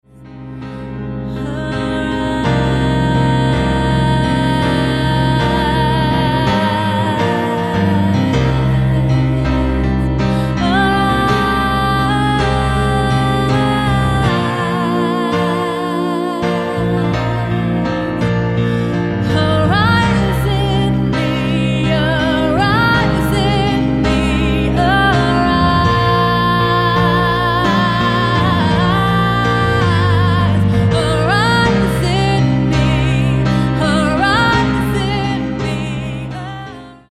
spontaneous worship with vocals and flowing keyboard.